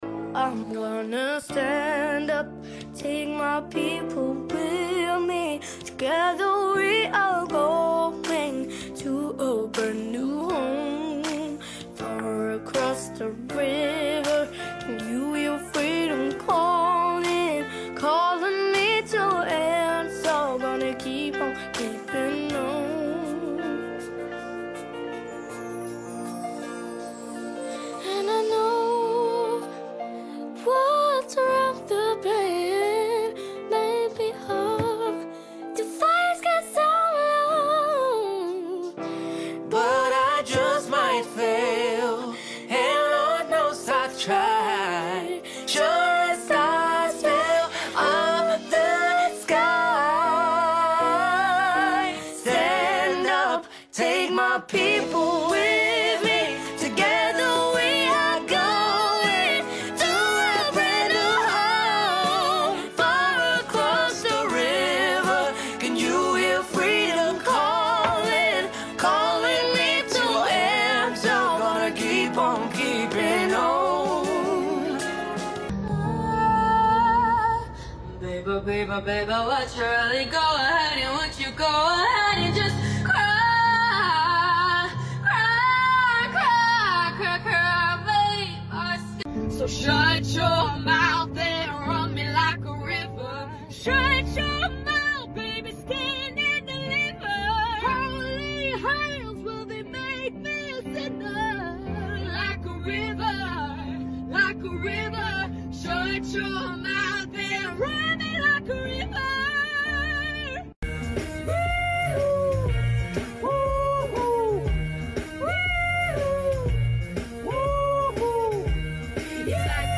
TOP 5 UNEXPECTED SINGING VOICES